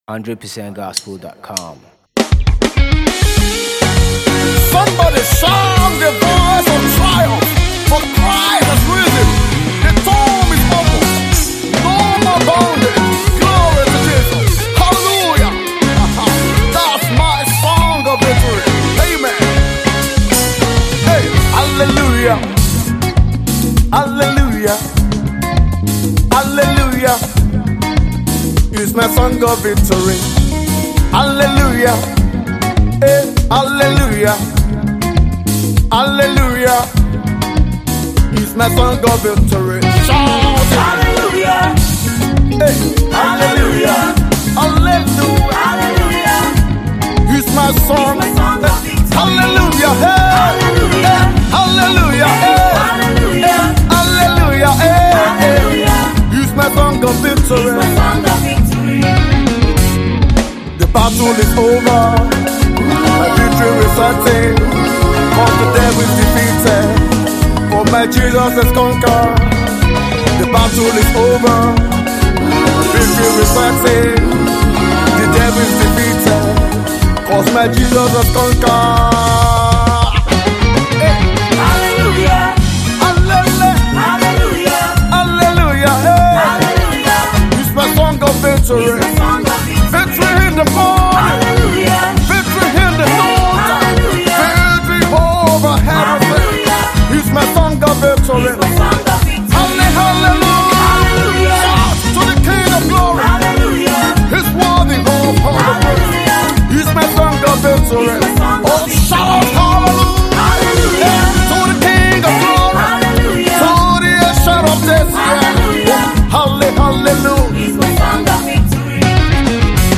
cool lyric-orchestrated praise song